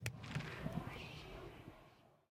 Minecraft Version Minecraft Version snapshot Latest Release | Latest Snapshot snapshot / assets / minecraft / sounds / block / trial_spawner / ambient4.ogg Compare With Compare With Latest Release | Latest Snapshot
ambient4.ogg